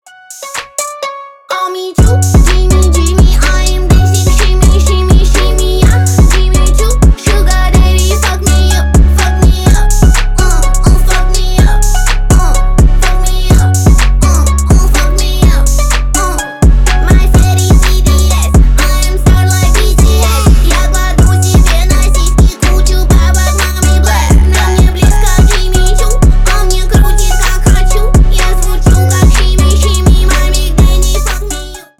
басы , женский рэп